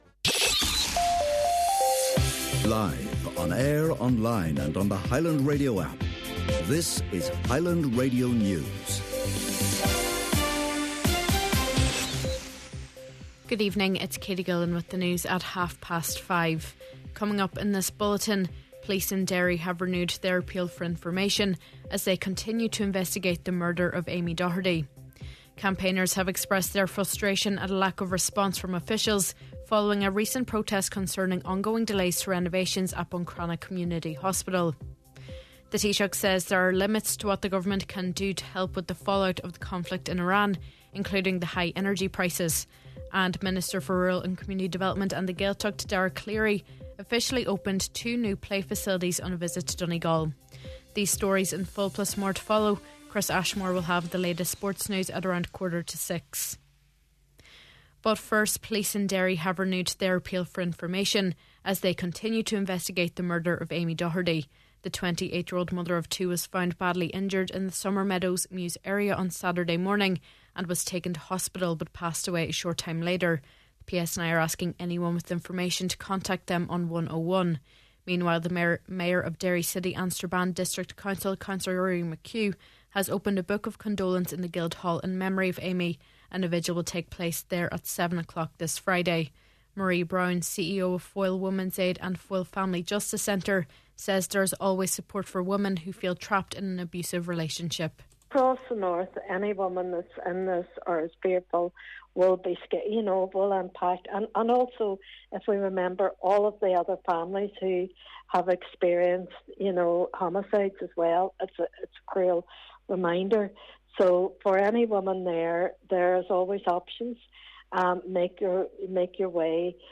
Main Evening News, Sport and Obituary Notices – Monday March 23rd